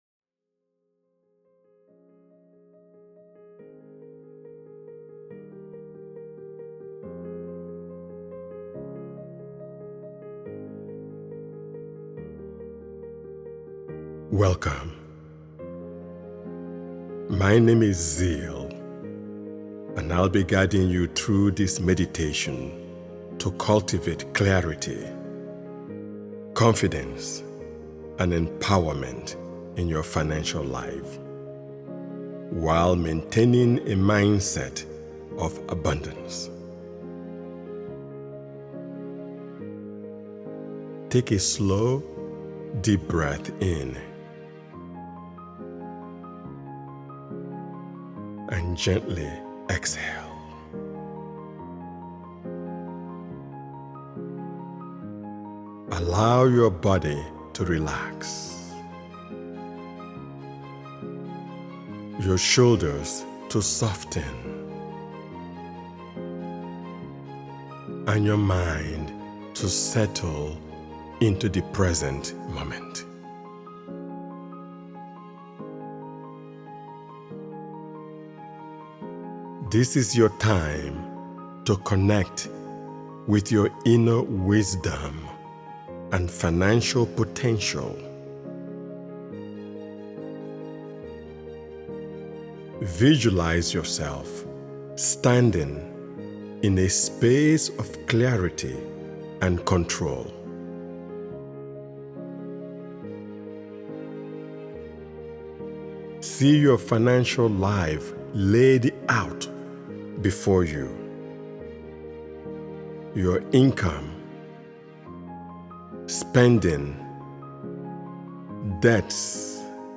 Money Mastery: Awaken Abundance and Inner Wealth is an empowering guided meditation designed to help you align your mindset, emotions, and intentions with the flow of true prosperity.